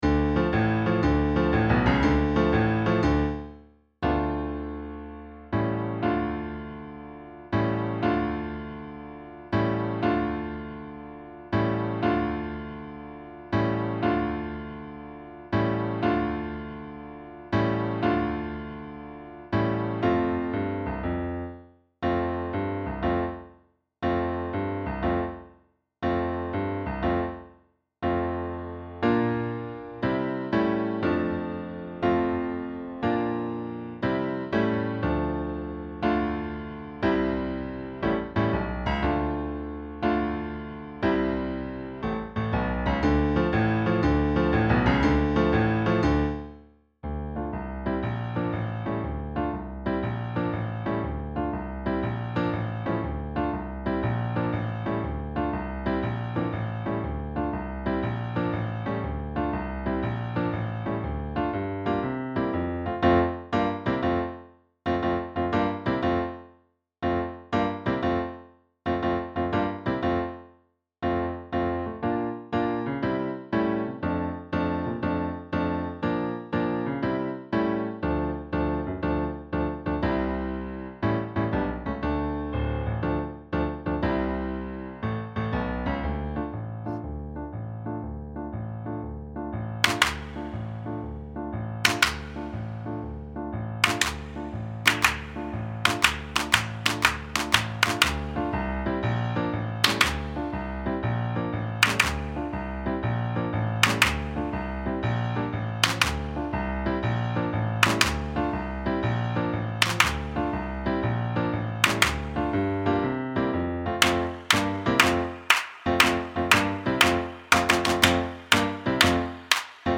Accompagnement
comptine_accompagnement.mp3